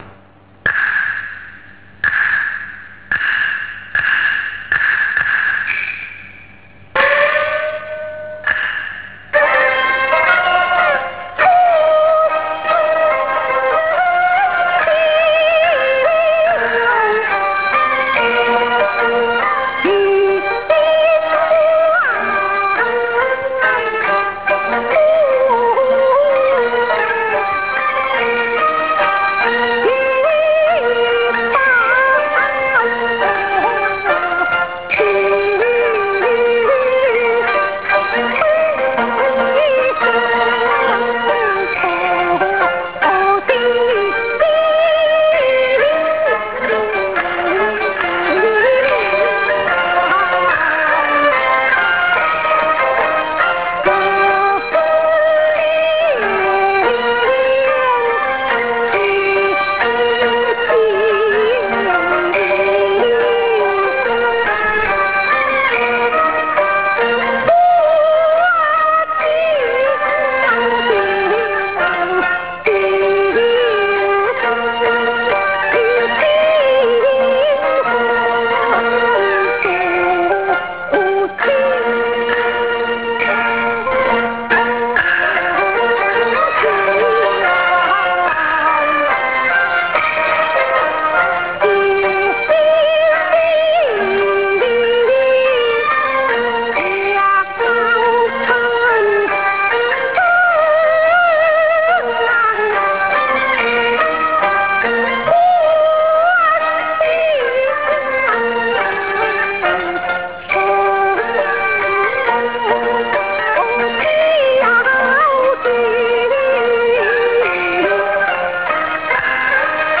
Beijing Opera Arias
Chun Qiu Pagoda 1: a Dan aria from Unicorn-trapping Purse;